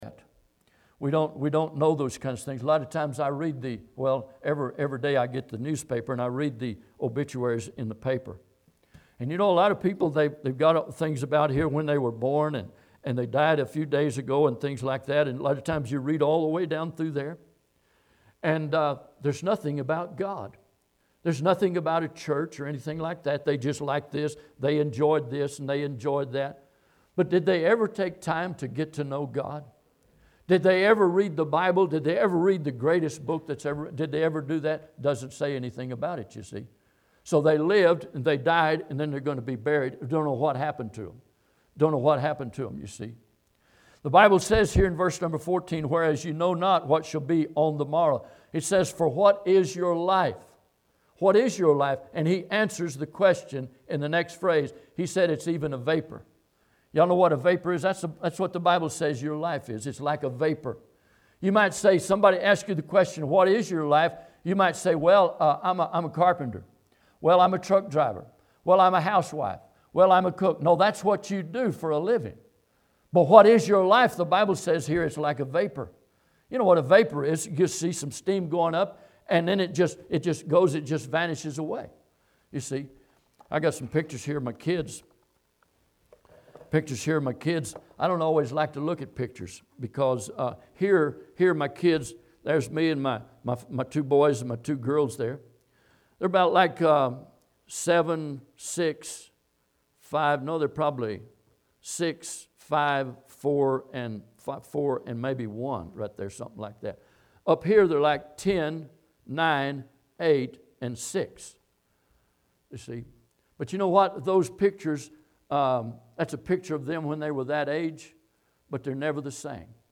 James 4:13-17 Service Type: Sunday am Bible Text